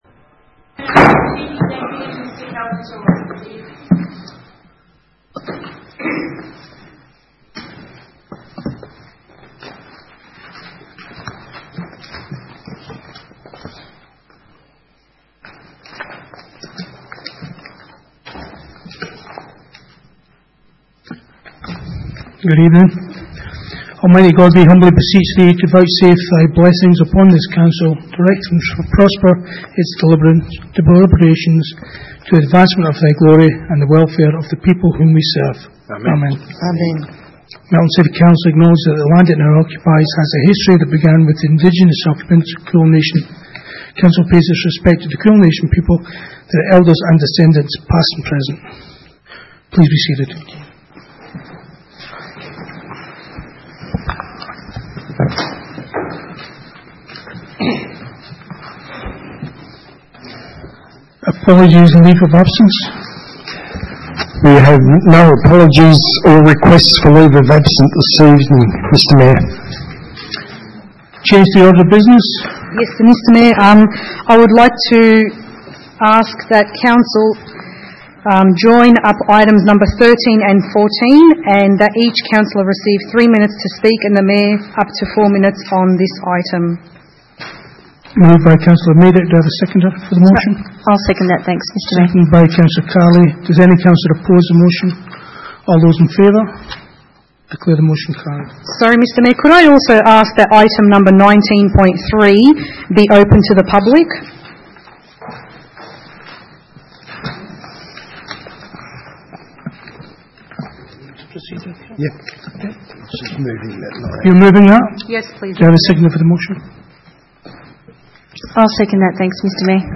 Ordinary meeting 17 September 2018